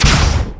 fire_tachyon3.wav